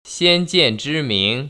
先见之明[xiān jiàn zhī míng]